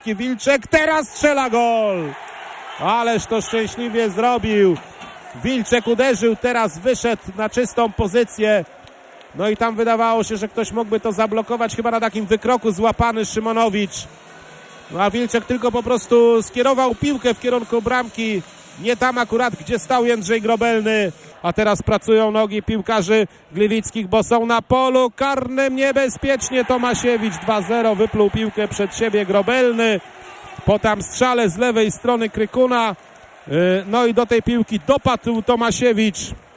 Mecz ze stadionu w Gliwicach